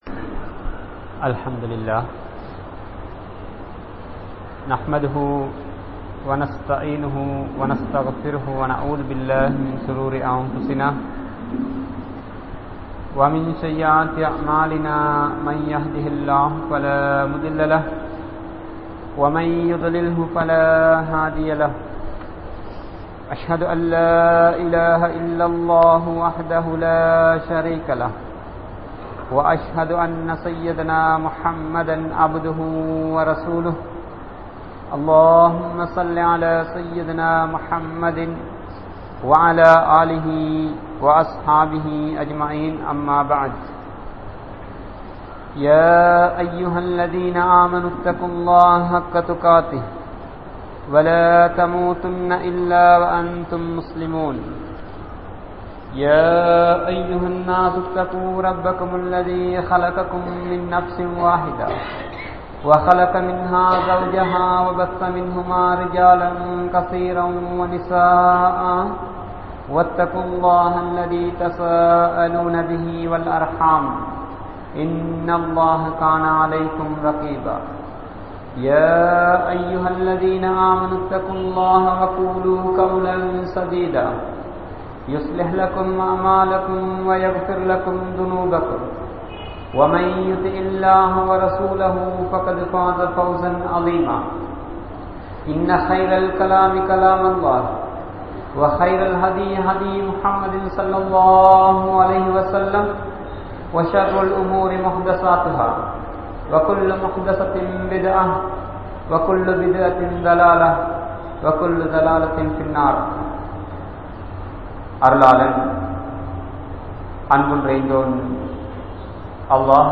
Samoohathil Idam Perum Paavangal(Sins) | Audio Bayans | All Ceylon Muslim Youth Community | Addalaichenai
Grand Jumua Masjith